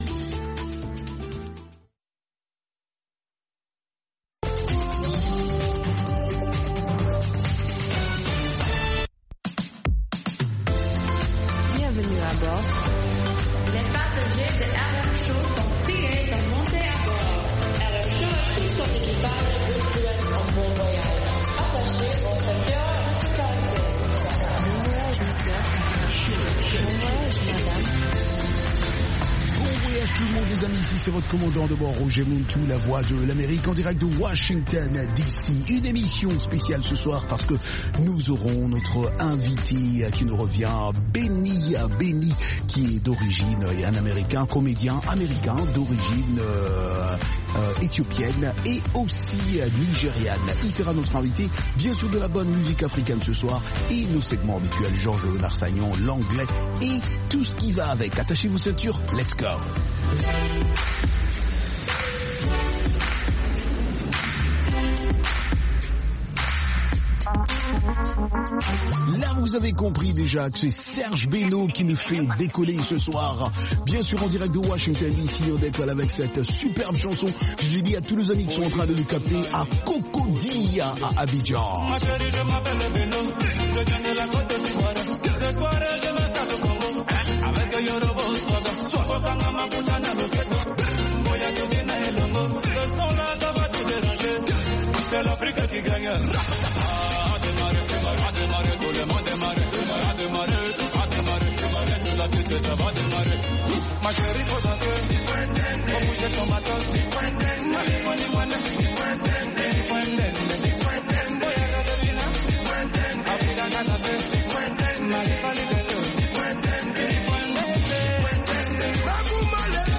propose notamment d'écouter de la musique africaine